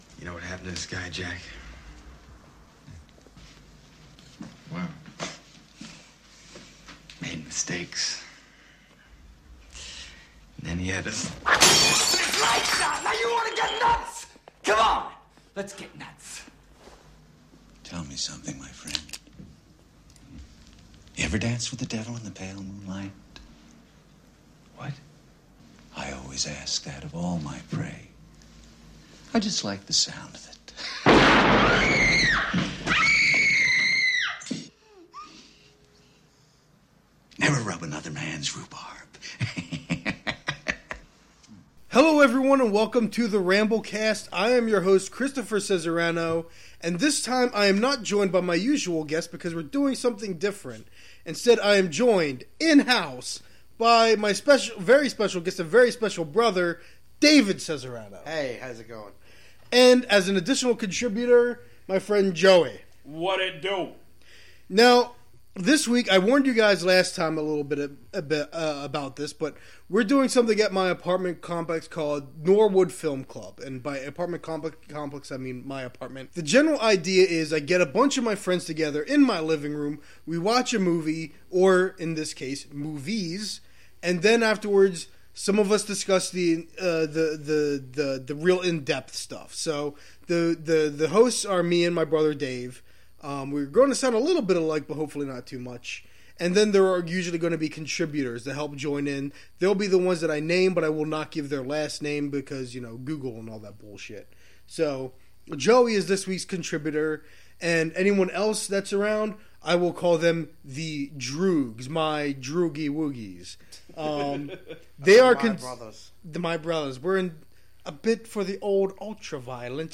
It’s a bit of a rough cut filled with a lot of lessons learned. The first being that it’s really not a good idea to be rather drunk when you record.
So hopefully future episodes will not be so sloppy.